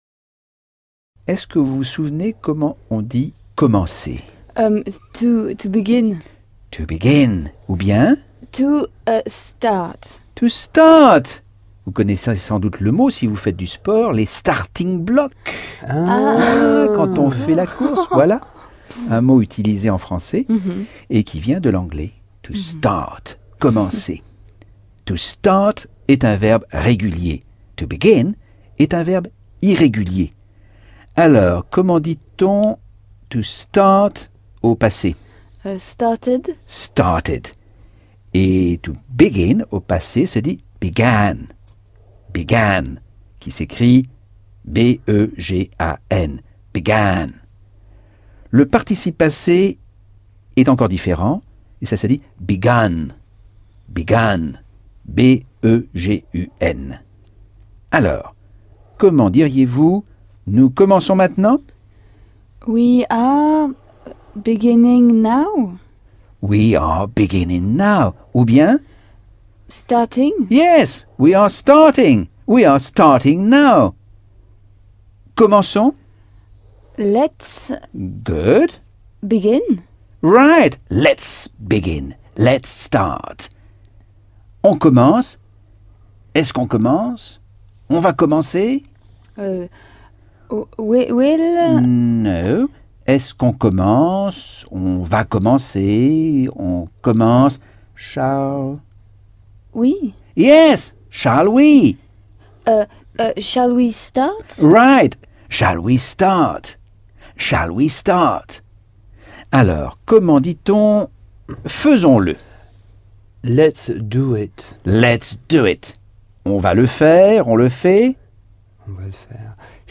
Leçon 1 - Cours audio Anglais par Michel Thomas - Chapitre 10